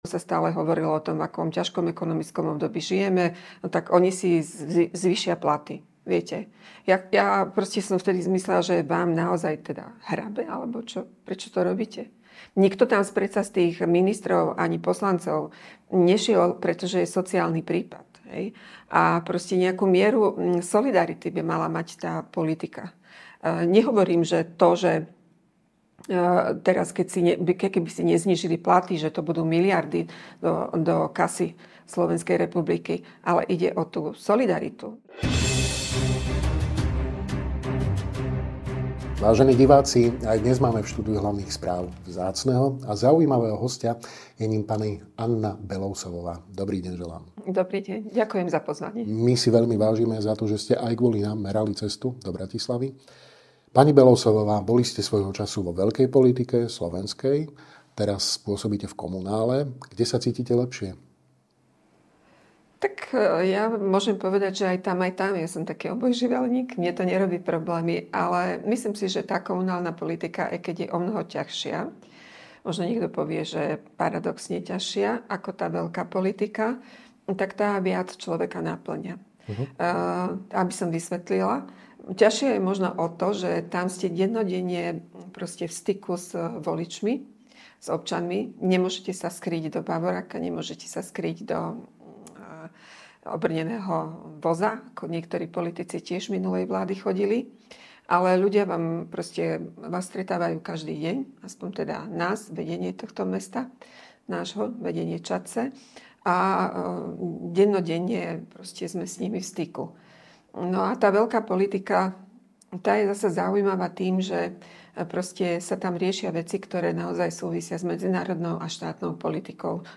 Dozviete sa vo videorozhovore s dlhoročnou političkou, momentálne na komunálnej úrovni, RNDr. Annou Belousovovou.